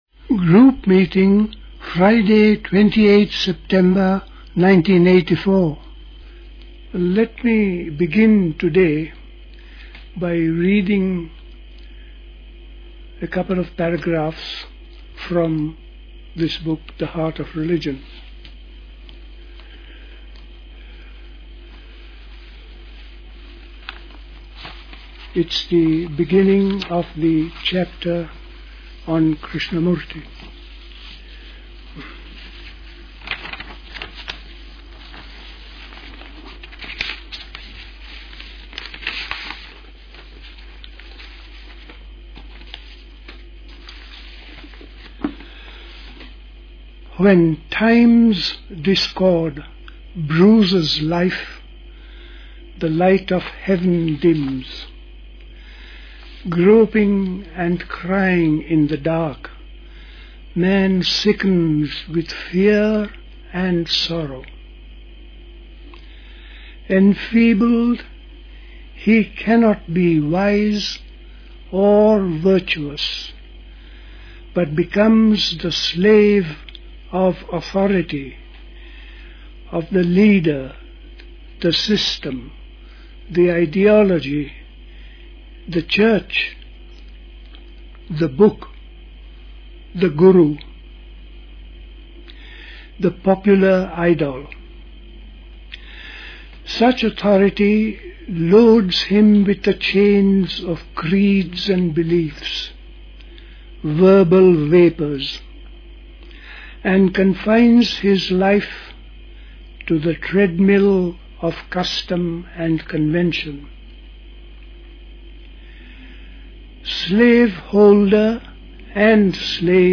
The Heart of Religion page 121 read. Freedom from fear is indispensable for living the religious life. Man’s misconceptions of religious living.